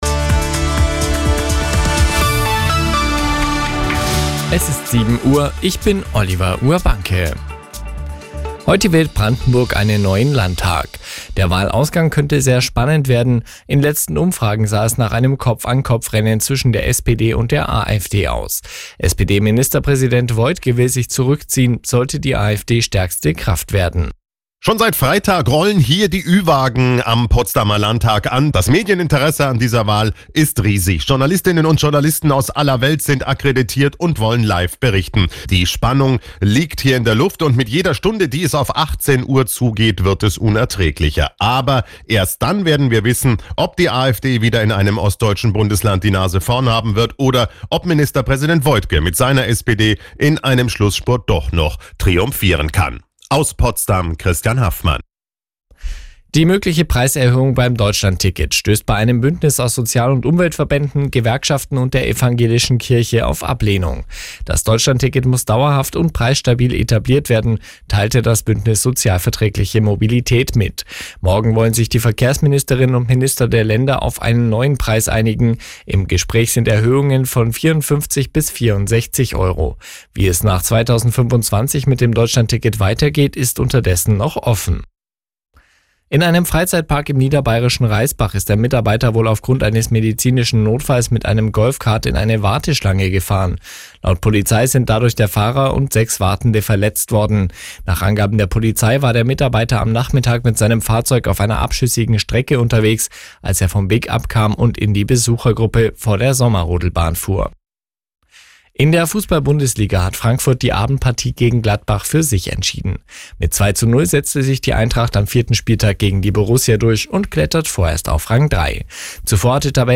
Die Arabella Nachrichten vom Sonntag, 22.09.2024 um 08:59 Uhr - 22.09.2024